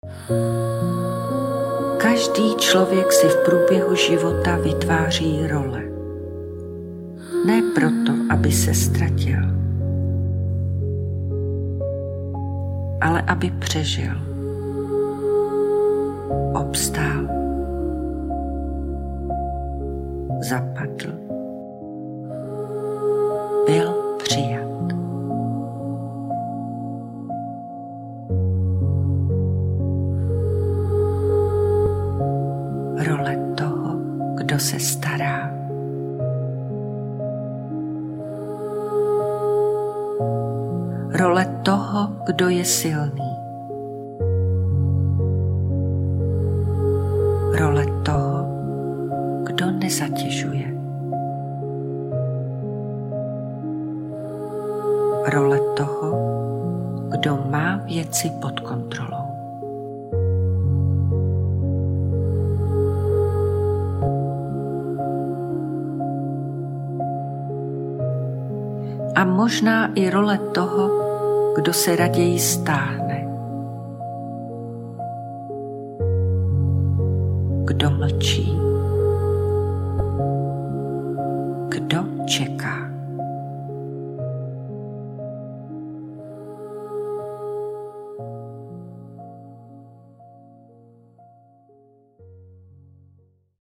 obsahuje čtyři vedené meditace, které na sebe jemně navazují, ale můžeš je používat i samostatně podle aktuální potřeby.